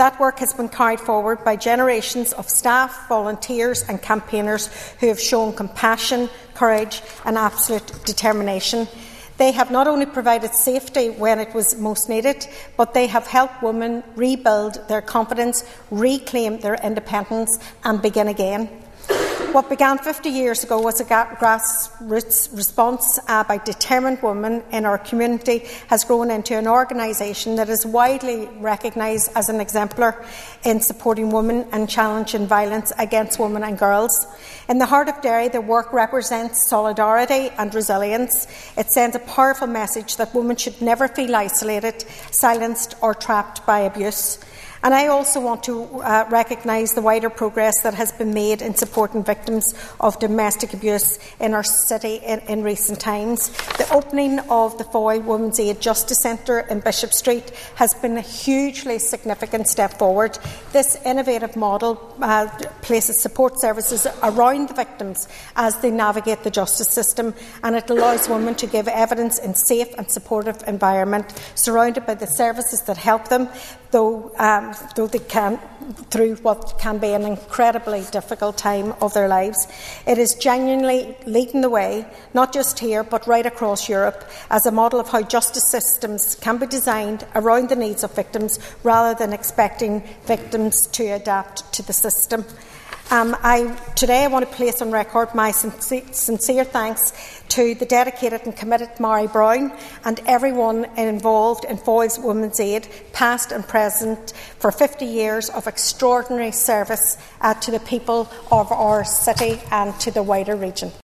The work of Foyle Women’s Aid has been recognised on the floor of the Assembly at Stormont.
Ms McLaughlin told MLAs that, in addition to providing safety, Foyle Women’s Aid has helped people rebuild their confidence and their lives.